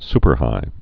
(spər-hī)